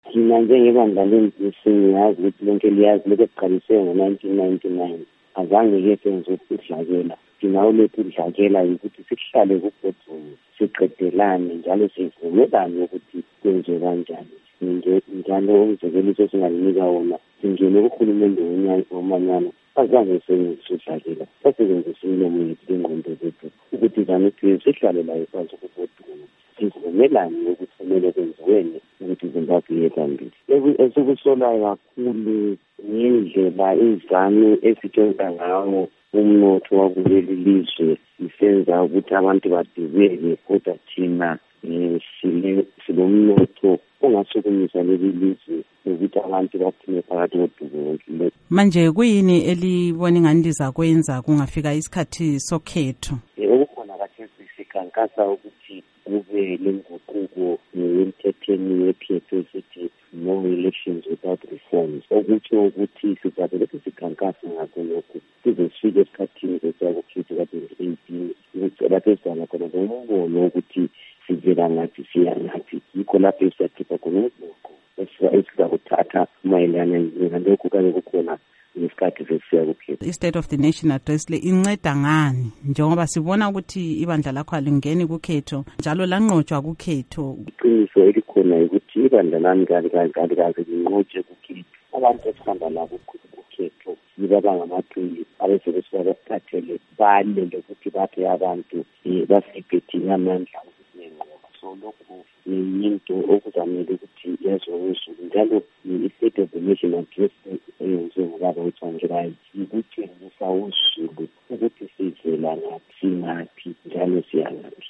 Ingxoxo LoNkasazana Thabitha Khumalo